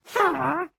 mob / witch / ambient2.ogg
ambient2.ogg